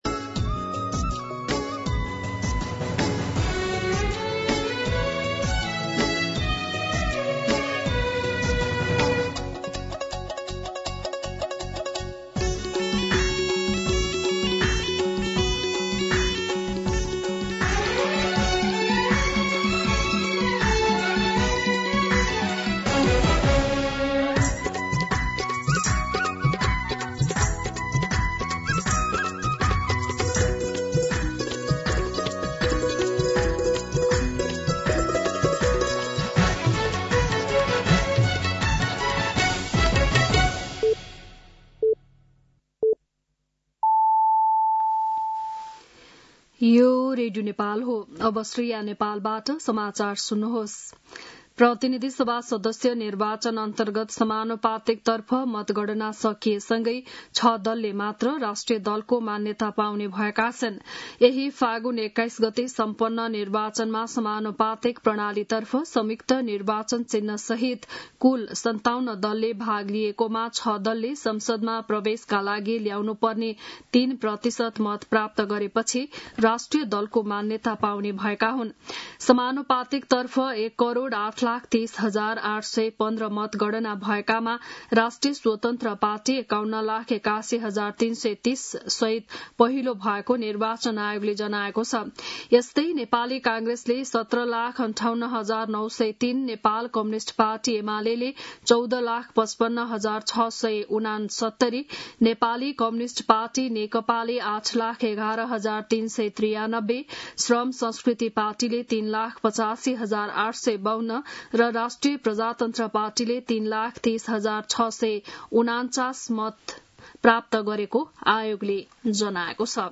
बिहान ११ बजेको नेपाली समाचार : २८ फागुन , २०८२